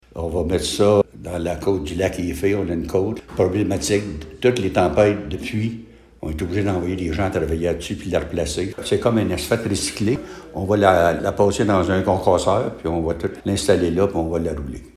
Cet asphalte recyclé sera étendu dans le secteur du lac Heafey, comme l’indique le maire, Ronald Cross :